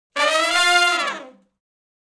012 Fast Climb Up (F) unison.wav